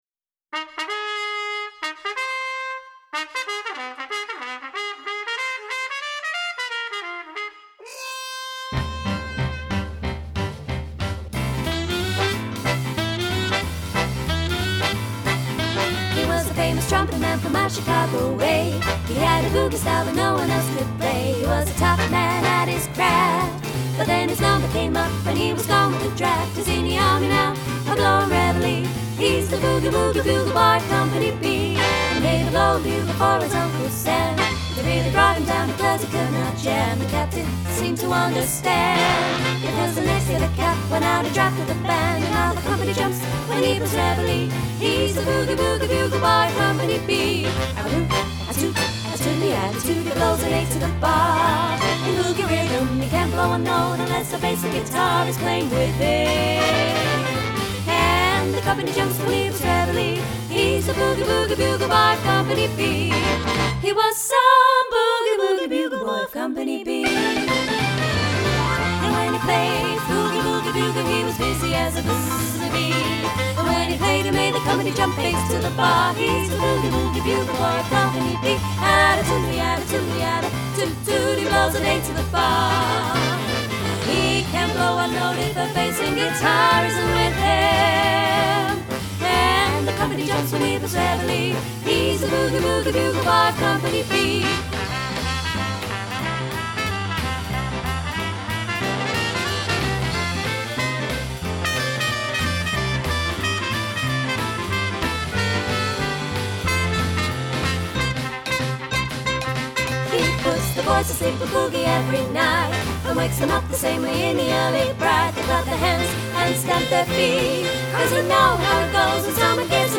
Three Female Vocal Harmony Speakeasy Swing Band for Hire
3 x Vocalists, Backing Tracks